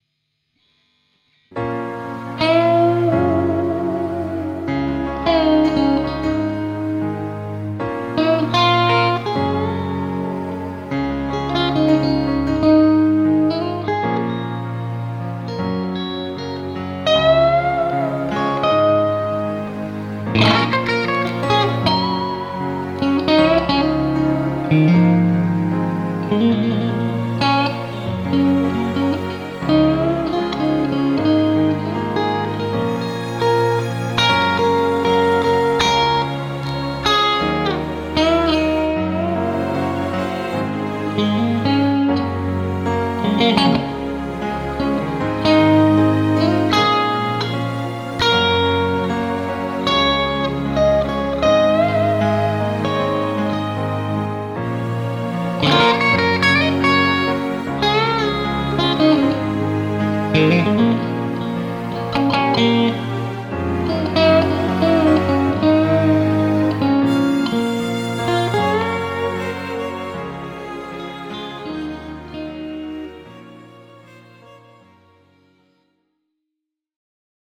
hyvä balanssi
Hieman kaipasin sustainta ja attackia, elikkä hieman liian varovaista omaan makuuni.
Mukavan mietiskelevä meininki ja malttia matkassa. Alussa toimiva taustaa seuraileva melodialinja.